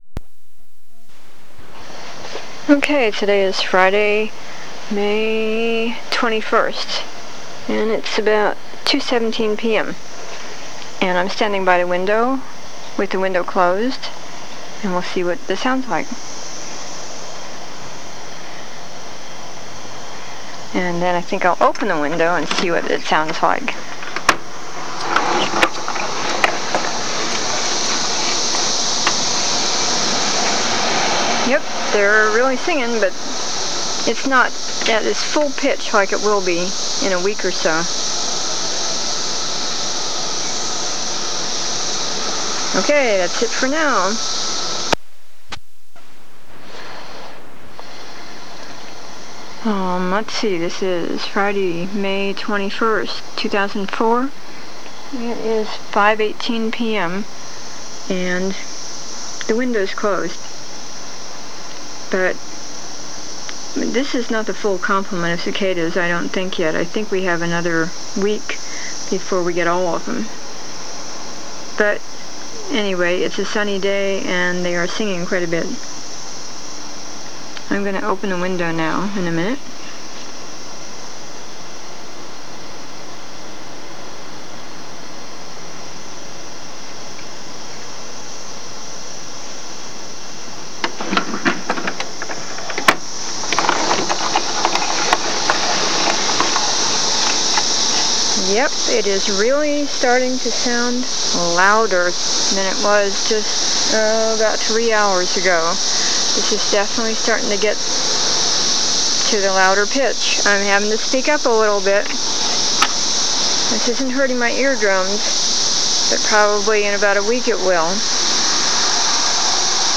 Warning: This is cicada sound, but with a LOT of commentary.
~~ (each day gets progressively louder) ~~
The second was recorded from indoors, at treetop level. Not very loud sounds yet at all.